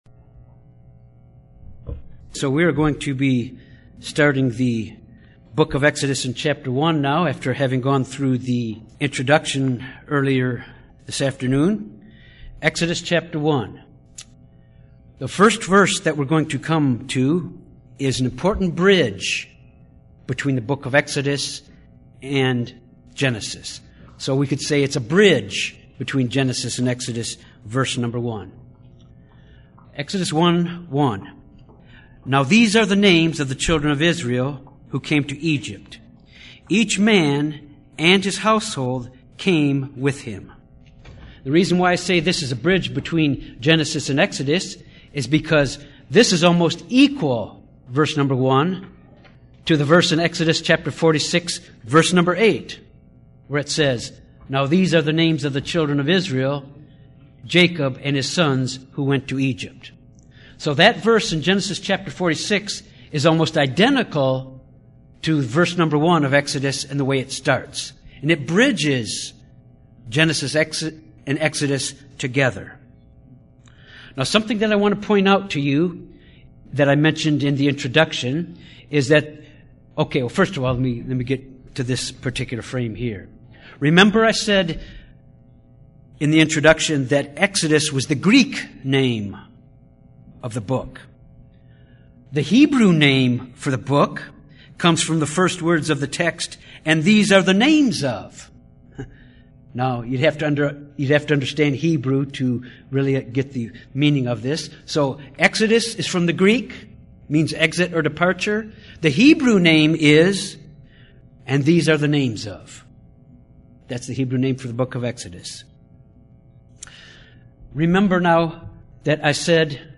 This Bible Study deals with the change in the condition of the Children of Israel from the time of Joseph until the time of Moses